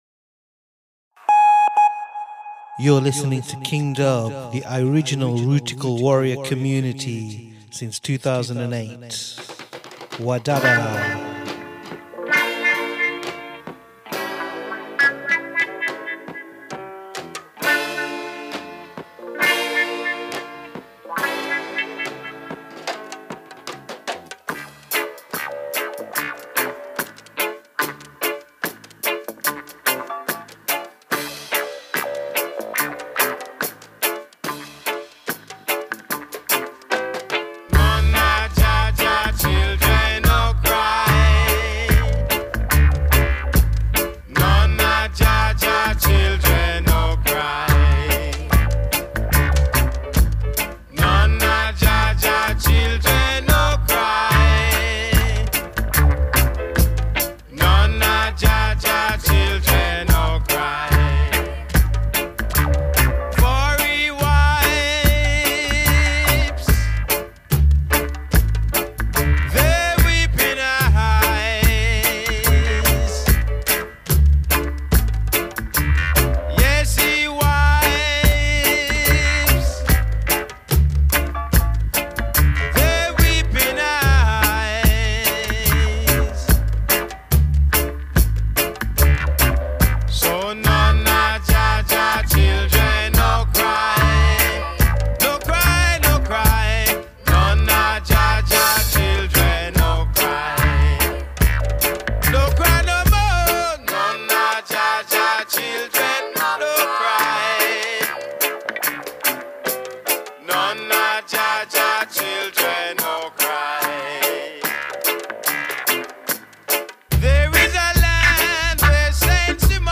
Conscious and meditative roots reggae and dub music